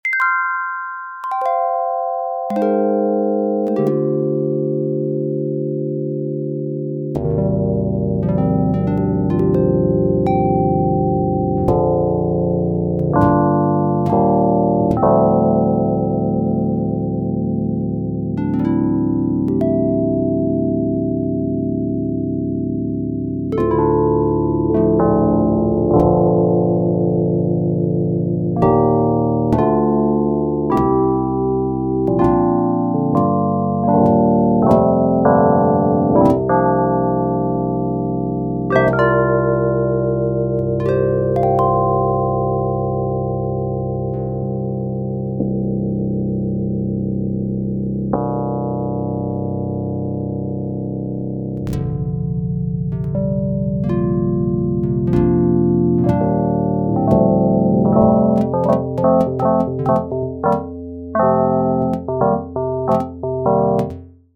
Program je napísaný v jazyku Java a matematicky modeluje zvuk trochu podobný elektrickému klavíru Fender Rhodes. Ide o symetrickú odrodu „Karplus-Strong“ algoritmu.
Tu je „surová“ nahrávka prvej verzie (zatiaľ s chybami - zatlmenie tónu zatiaľ spôsobuje krátke ostré rozoznenie):
Ako počuť z nahrávky, zvuk je na nízkych tónoch podobný klavíru Fender Rhodes; pri vyšších tónoch to zatiaľ nie je ono - chcelo by to viac prepracovať parametre modelu.